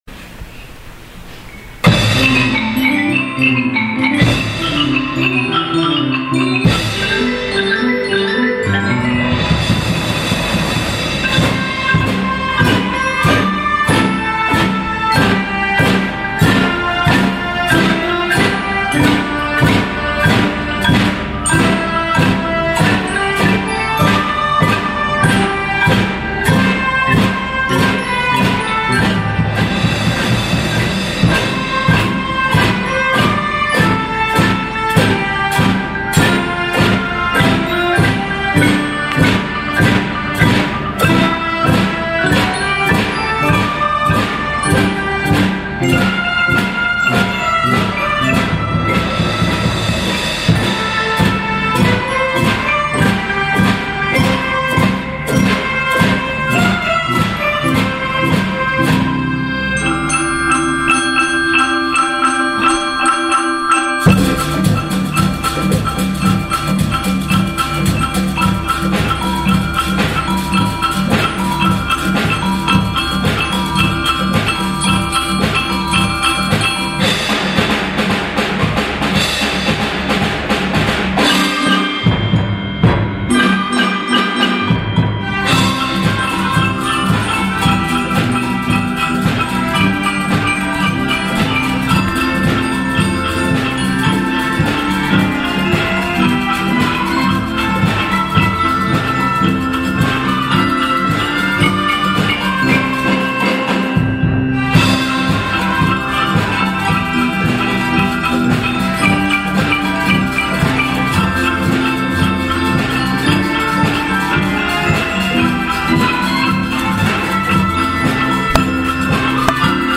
５．６年生合同発表会
２月８日（水）に、高学年の合同発表会が行われました。
高学年の子どもたちの奏でる音色は、力強さと軽快さを織り交ぜた多彩な音色で、会場の聴衆も息を飲み、耳を傾けていました。